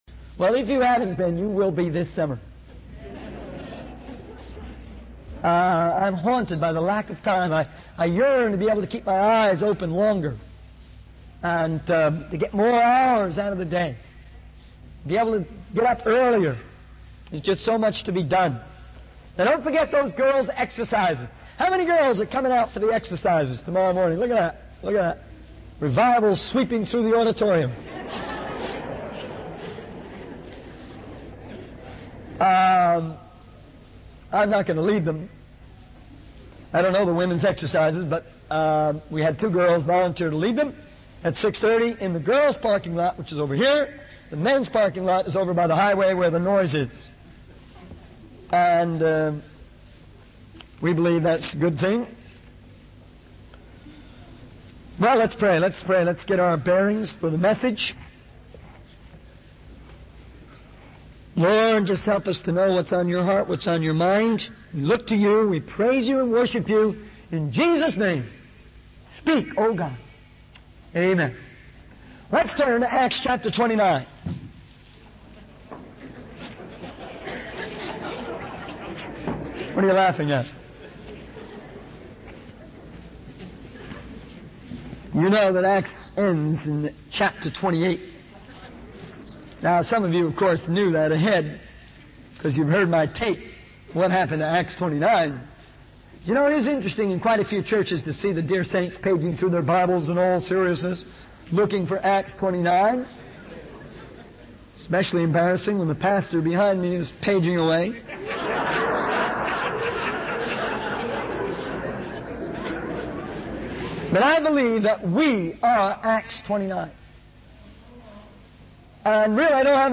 In this sermon, the speaker shares personal experiences and testimonies of God's provision and guidance in their ministry.